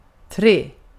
Uttal
Uttal Okänd accent: IPA: /treː/ Ordet hittades på dessa språk: svenska Ingen översättning hittades i den valda målspråket.